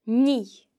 The slender N sound is made by pressing the tongue against the palate, and is made when the N occurs next to e or i in a word. The slender N can be heard in nigh (wash):